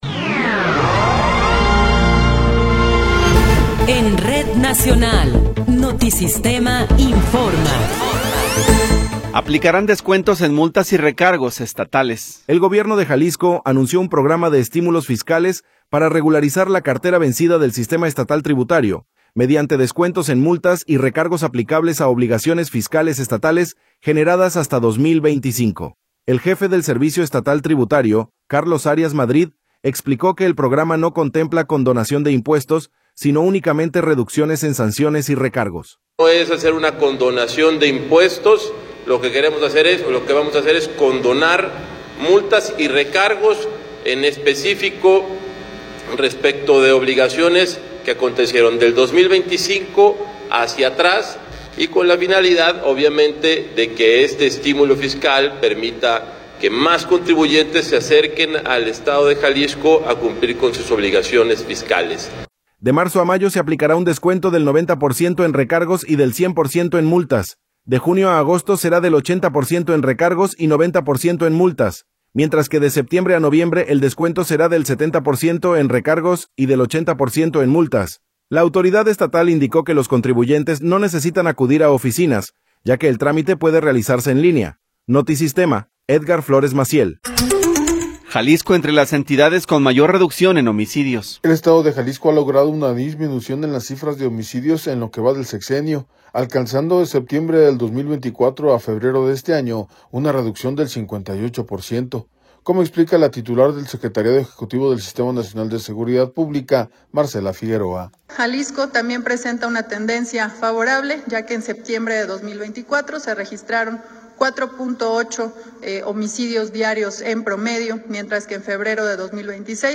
Noticiero 11 hrs. – 10 de Marzo de 2026